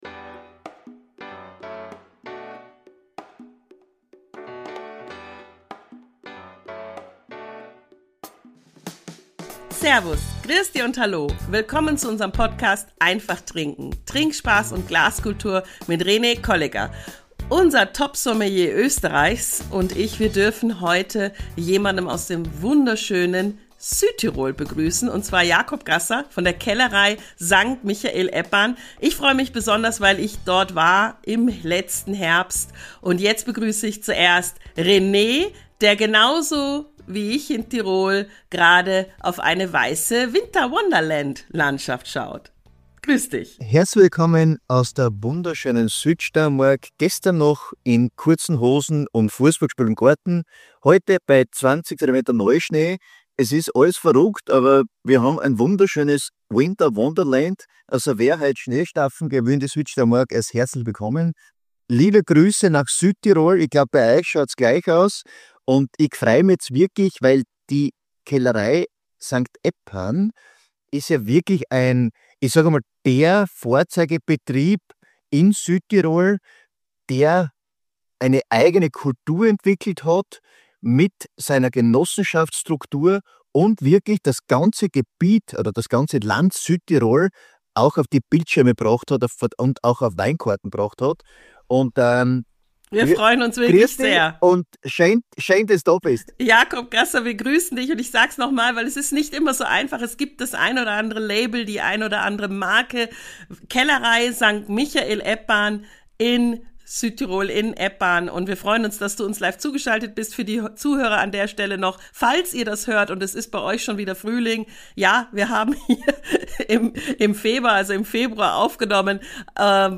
#102 Winzertalk mit der Kellerei St. Michael-Eppan, Südtirol ~ Einfach Trinken Podcast